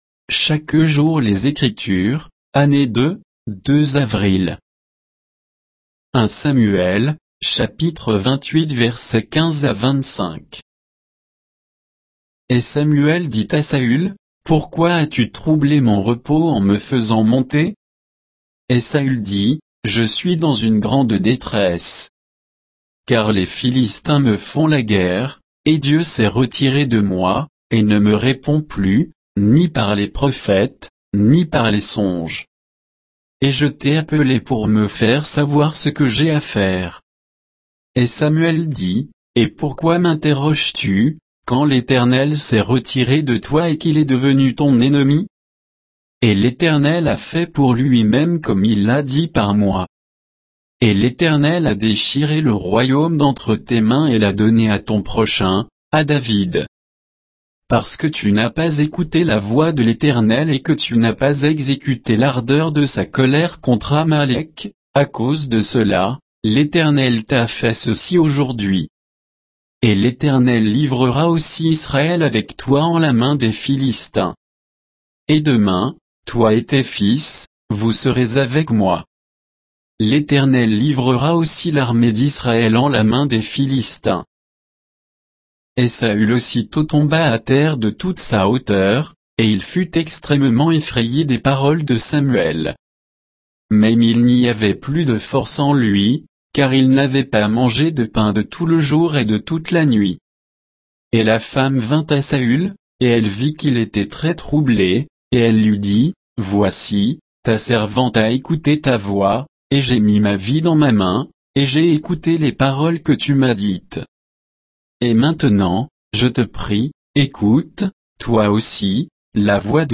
Méditation quoditienne de Chaque jour les Écritures sur 1 Samuel 28, 15 à 25